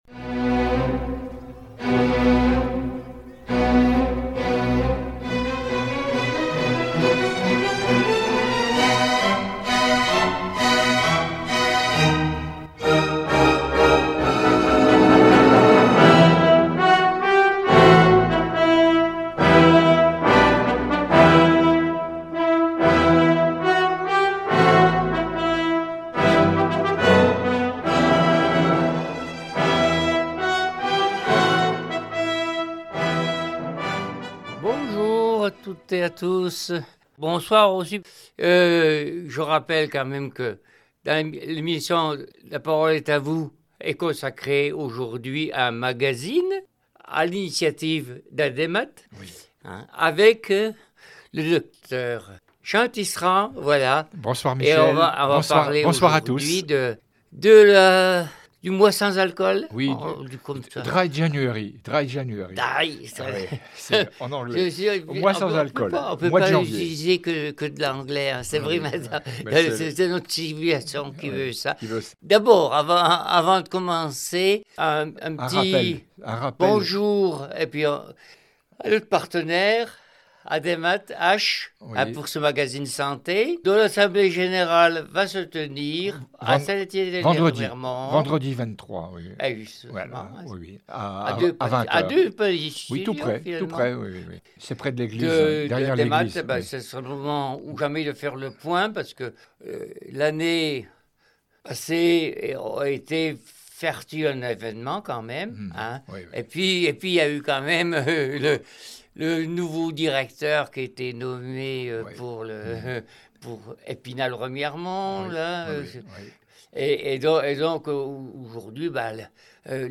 Symptômes de dépendance, effets sur le corps, risques avant 25 ans, grossesse, cancers et sécurité routière : une heure de discussion pour faire le point, informer et mieux comprendre les enjeux de santé